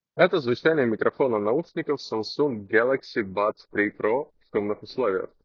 Samsung Galaxy Buds 3 Pro — Микрофон:
Звучание микрофона Samsung Galaxy Buds 3 Pro на 8 из 10  — немного хуже, чем у Apple Airpods Pro 2.
В шумных условиях:
samsung-galaxy-buds-3-pro-shum.m4a